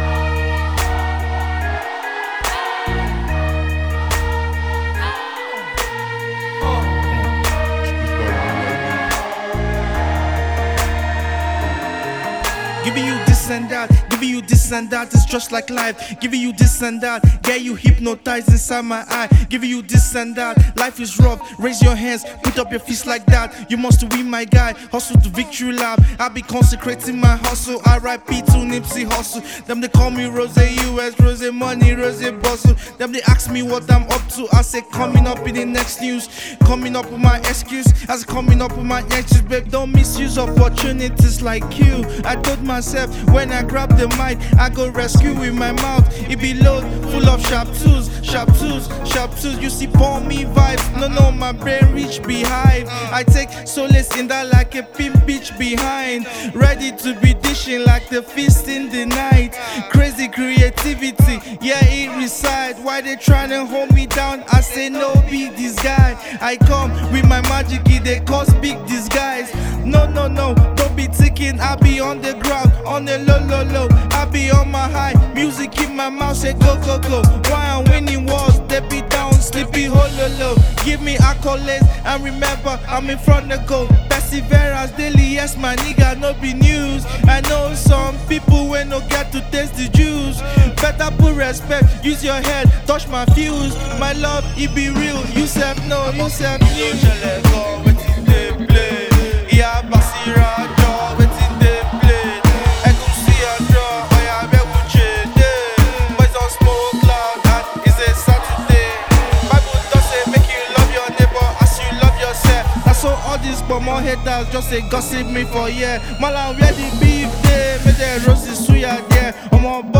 FCT Abuja Based Afro-hip-hop rapper